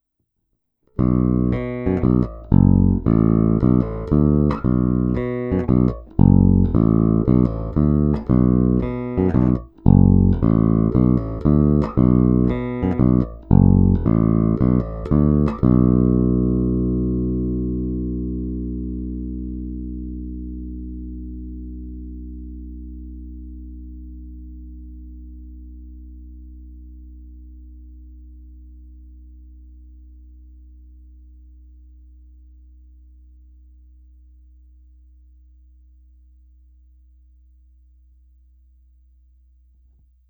V každé poloze je zvuk hodně konkrétní, pevný, zvonivý.
Není-li uvedeno jinak, následující nahrávky jsou provedeny rovnou do zvukové karty, jen normalizovány, jinak ponechány bez úprav.
Hráno vždy mezi snímači, korekce ponechány ve střední poloze.